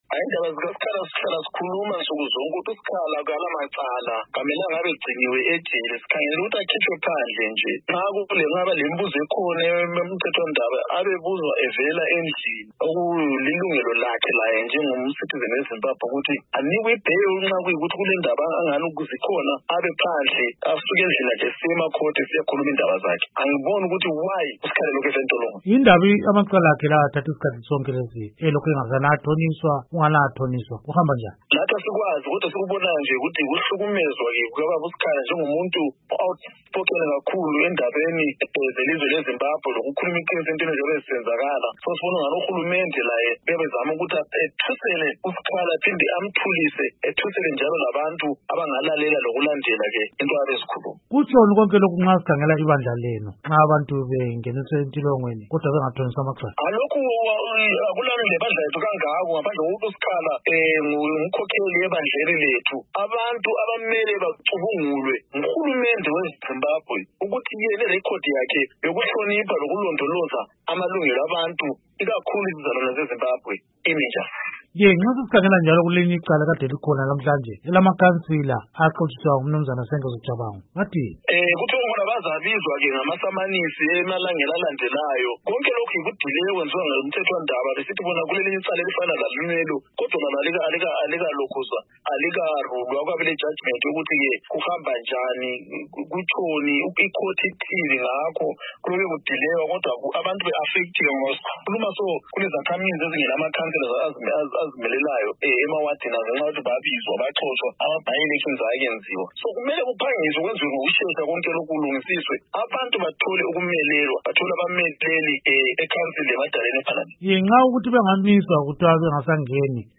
Ingxoxo Esiyenze